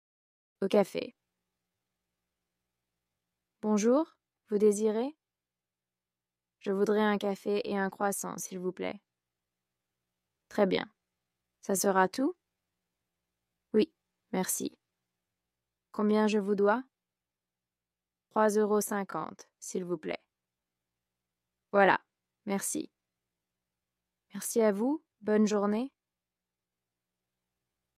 Dialogue FLE – Au café (niveau A2)